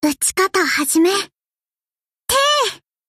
Ship Voice Natsugumo Attack.mp3
Ship_Voice_Natsugumo_Attack.mp3.ogg